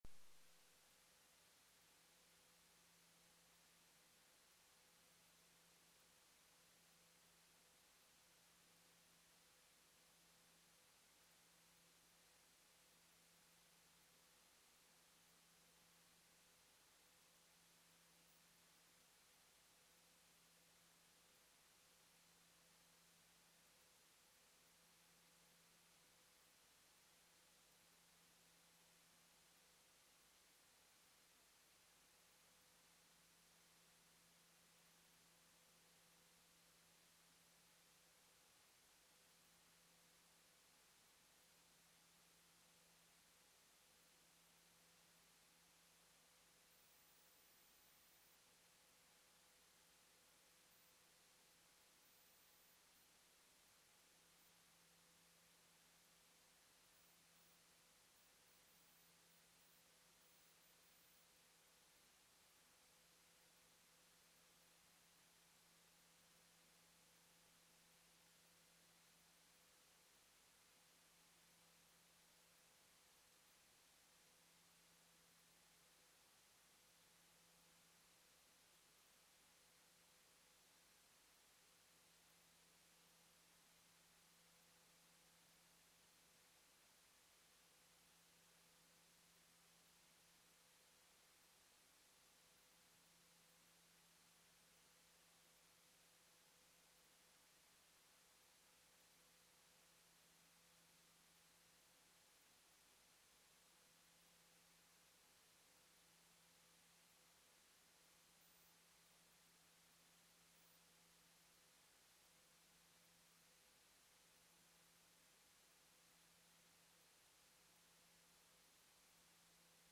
Council Meeting- 24 March 2020
Notice is hereby given that a meeting of the Council of Burwood will be held in the Council Chamber, Suite 1, Level 2, 1-17 Elsie Street, Burwood on Tuesday 24 March 2020 at 6:00pm to consider the matters contained in the attached Agenda.